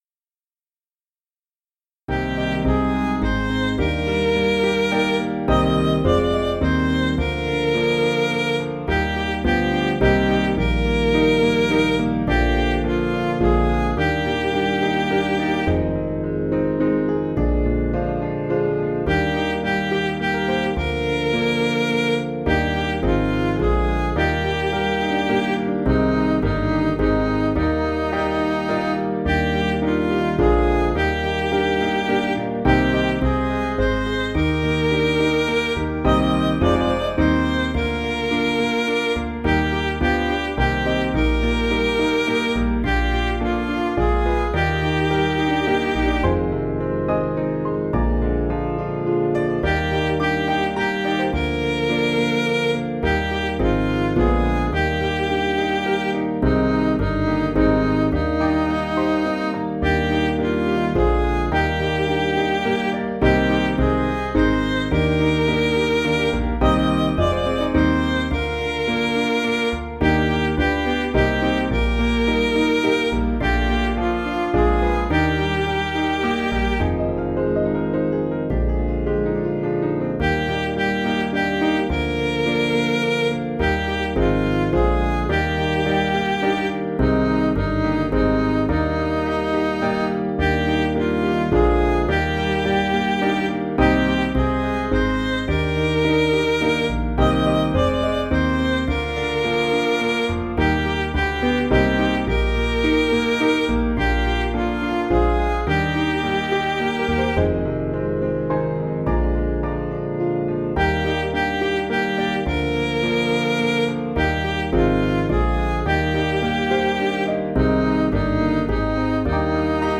Piano & Instrumental
(CM)   5/Eb
Midi